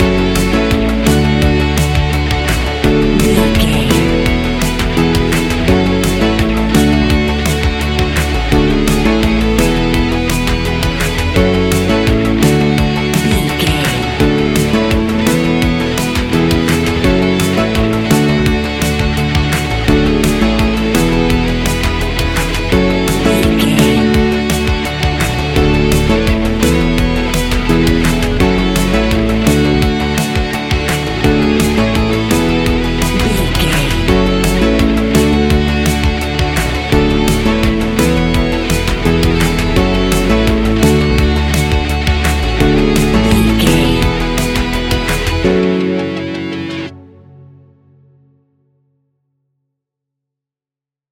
Ionian/Major
ambient
electronic
new age
chill out
downtempo
synth
pads